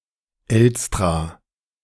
Elstra (German: [ˈɛlstʁaː]